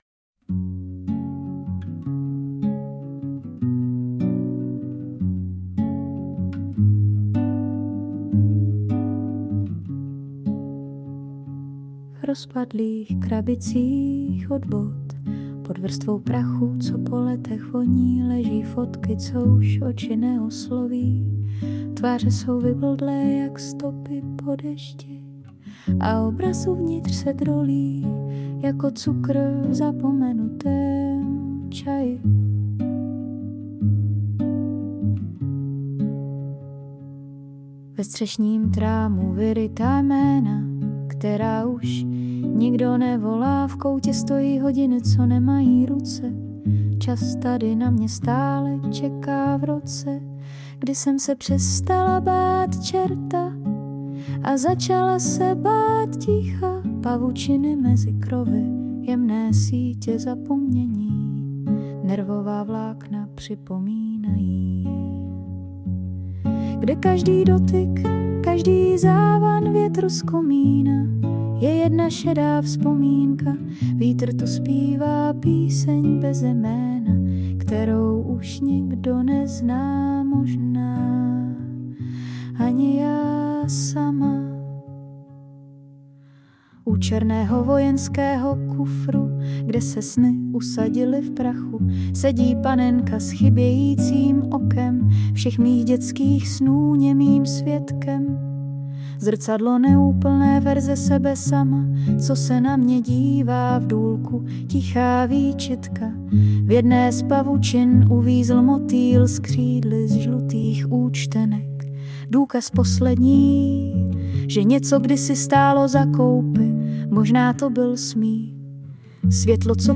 Abych předešla nedorozumění – zhudebnění dělá moje dcera v hudebních programech.